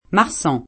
vai all'elenco alfabetico delle voci ingrandisci il carattere 100% rimpicciolisci il carattere stampa invia tramite posta elettronica codividi su Facebook Marsan [fr. mar S3^ ] top. (Fr.) e cogn. — anche cogn. ven. [it. mar S# n ]